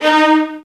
VIOLINS.FN-R.wav